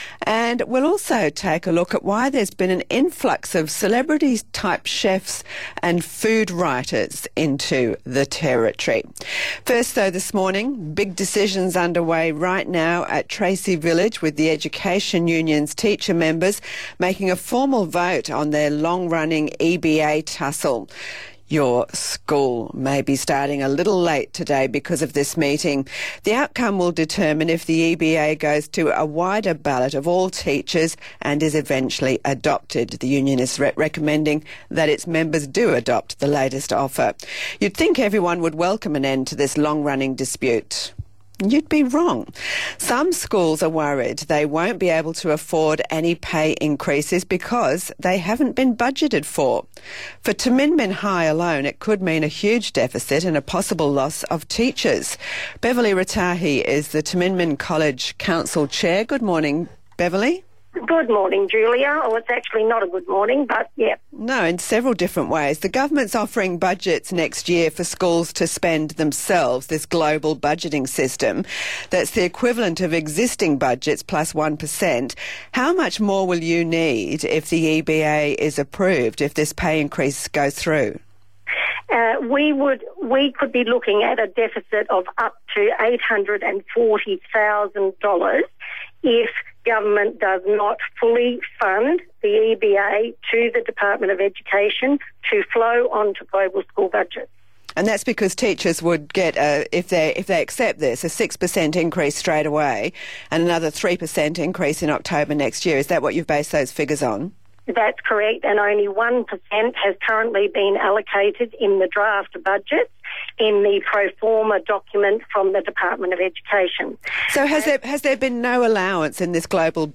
Media and interviews (opens in audio player)
Peter Chandler, Minister for Education, ABC 14 November 2014 - Global Budgets